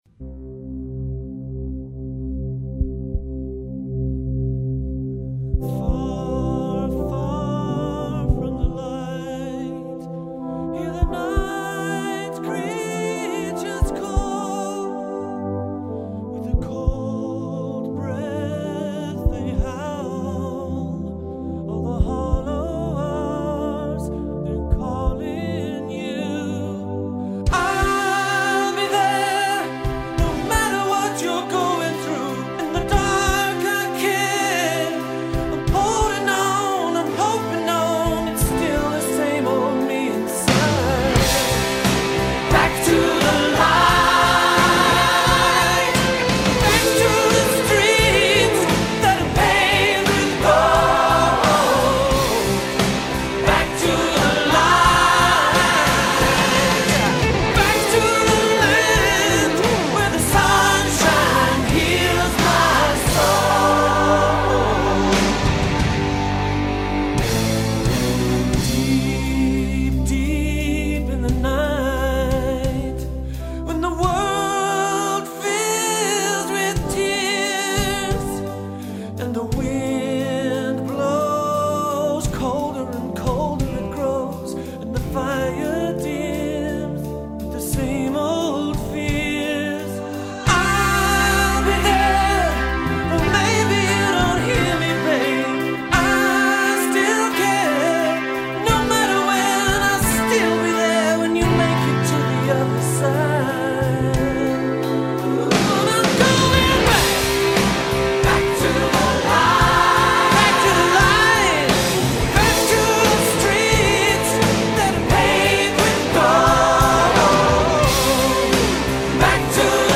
enérgicas piezas